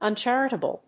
音節un・char・i・ta・ble 発音記号・読み方
/`ʌntʃˈærəṭəbl(米国英語)/